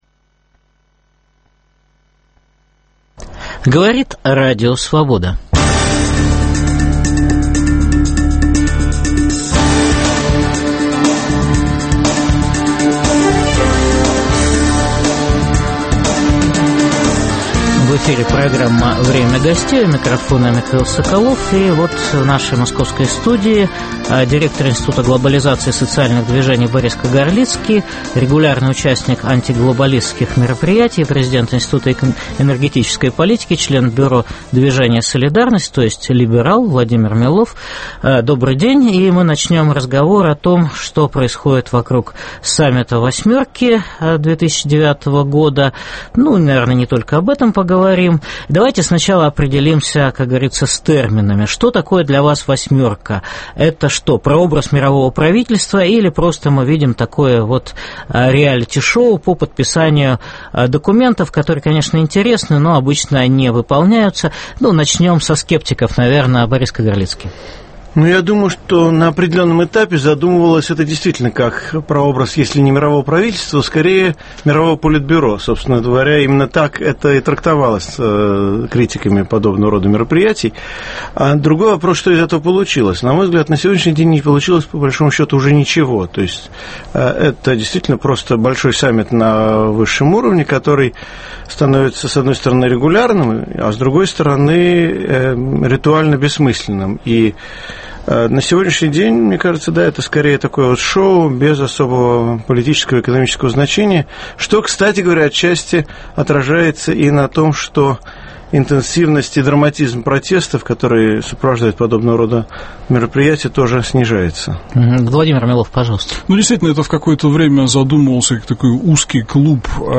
Тема программы: "Россия на саммите "восьмерки" 2009. В дискуссии участвуют: директор Института глобализации и социальных движений Борис Кагарлицкий и президент Института энергетической политики Владимир Милов.